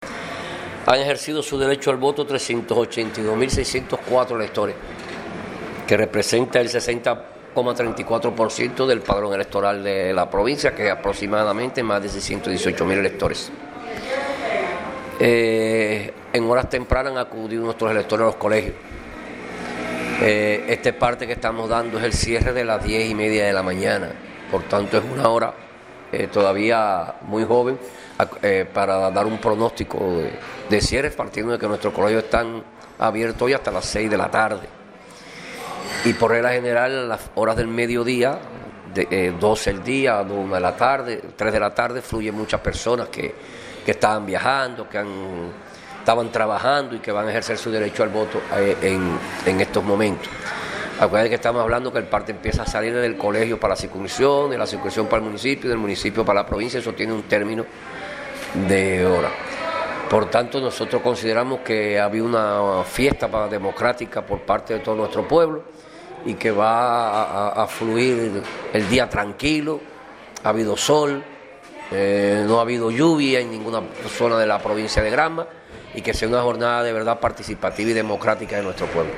Declaraciones de Antonio De Marcos Ramírez presidente de la CEP en Granma